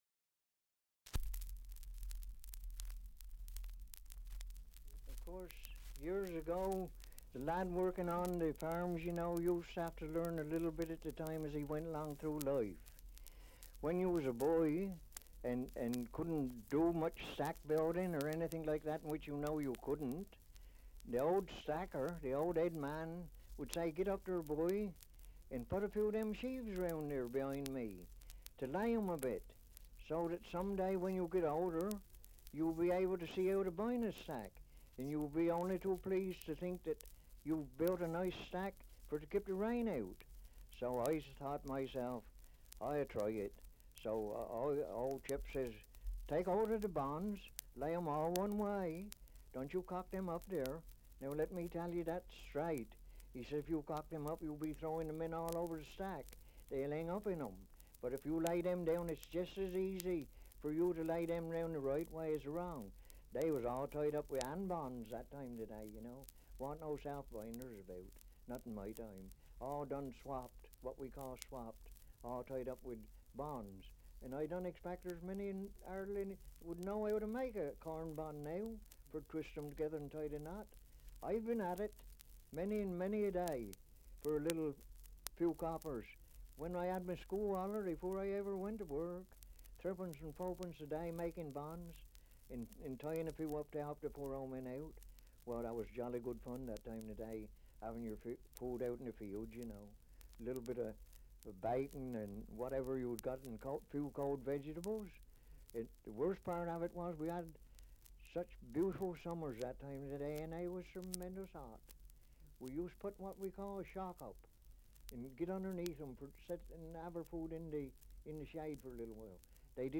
2 - Survey of English Dialects recording in Firle, Sussex
78 r.p.m., cellulose nitrate on aluminium